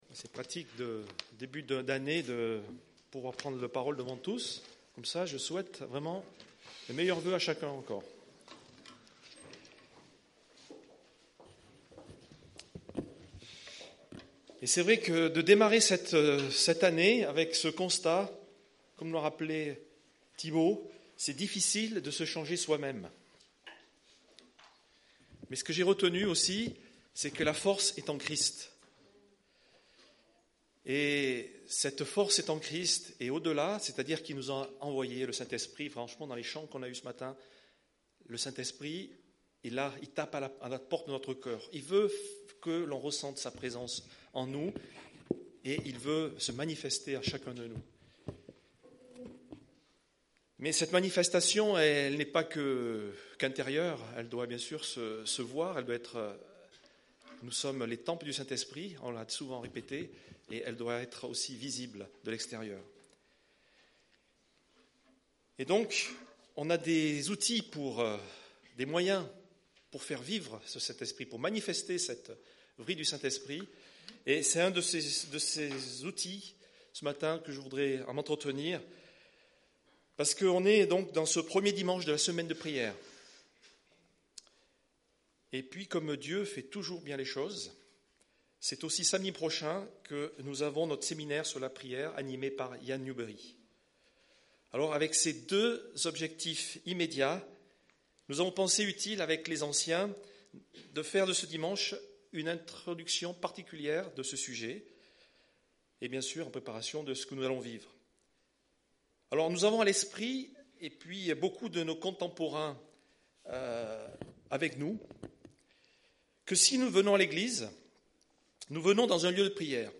Culte du 13 janvier